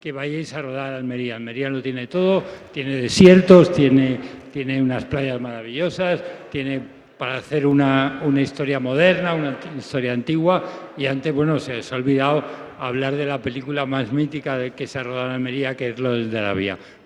La XXIV edición del certamen almeriense se ha presentado en un multitudinario acto celebrado en la casa de uno de sus principales colaboradores, en el Espacio Movistar del Edificio Telefónica, en la Gran Vía madrileña.
11-11_fical_madrid_gerardo_herrero.mp3